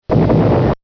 hit.mp3